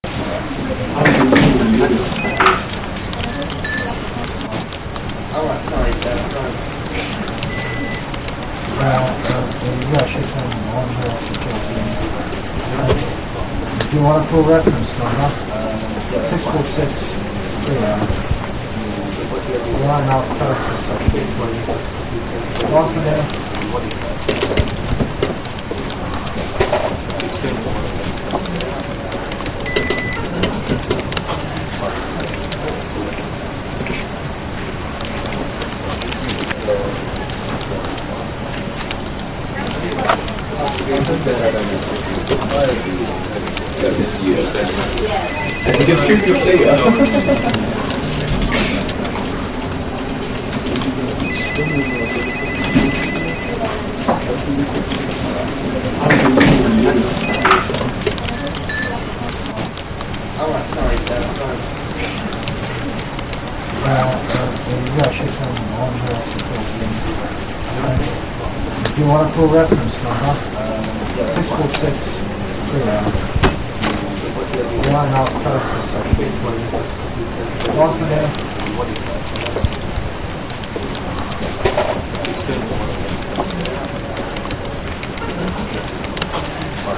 Background Ambient Noise
You can add background ambient noise to your AI agent, which will make the conversation sound more natural.
2. Office
office-noise.wav